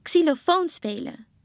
xylofoon_spelen.mp3